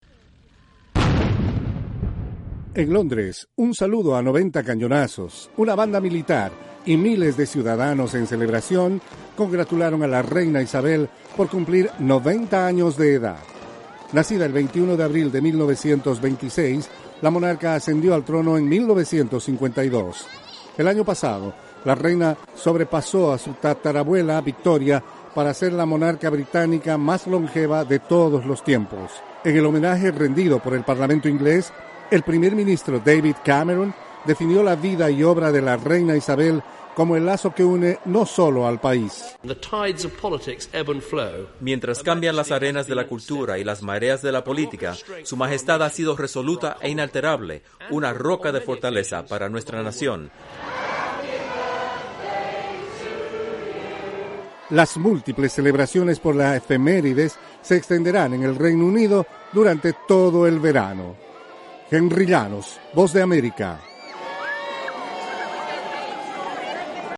Una enorme muchedumbre presenció los disparos de cañones que celebran el nonagésimo cumpleaños de la Reina Isabel de Gran Bretaña, la monarca de más longeva de todos los tiempos.